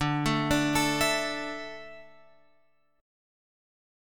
D Suspended 2nd